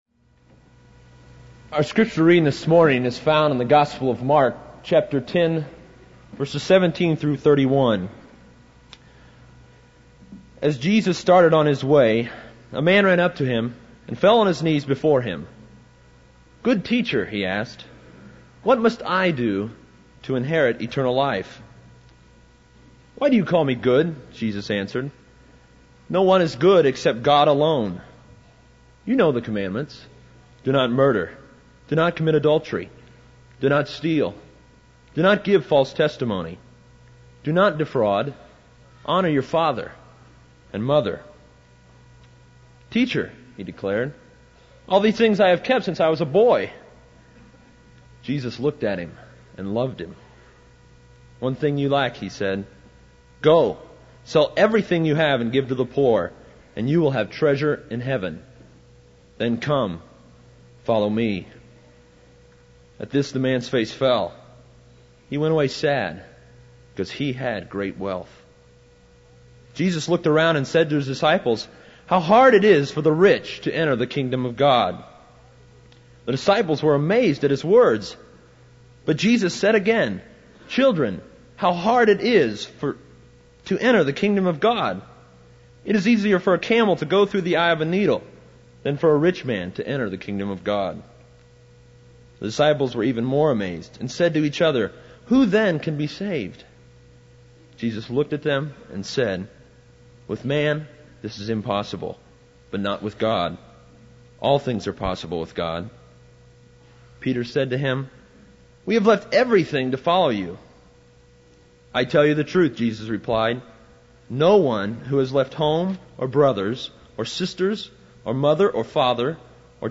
This is a sermon on Mark 10:17-31.